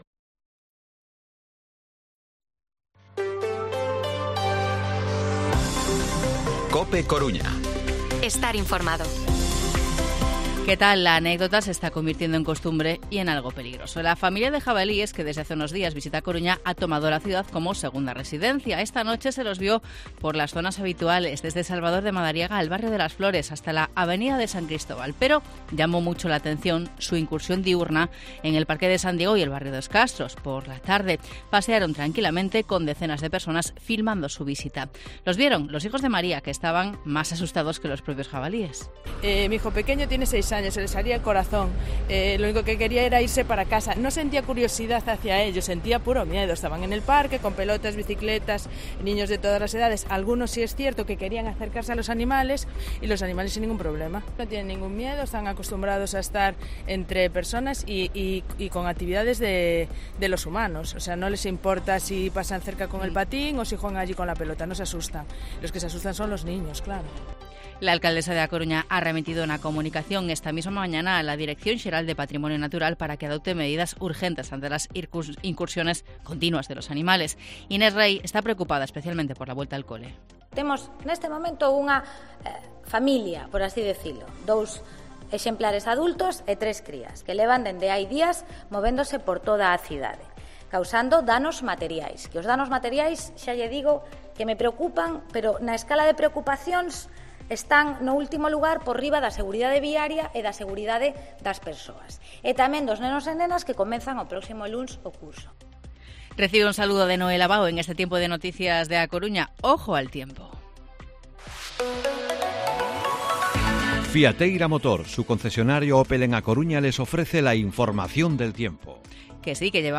Informativo Mediodía COPE Coruña viernes, 8 de septiembre de 2023 14:20-14:30